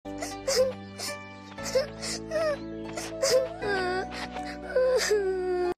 Kai Lan Crying